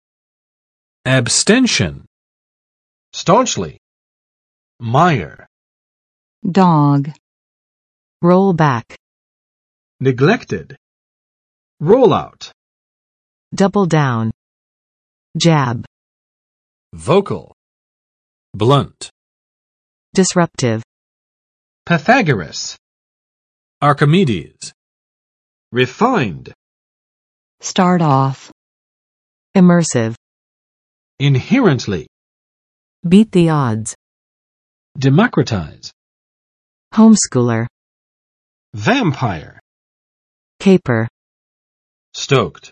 [æbˋstɛnʃən] n. 弃权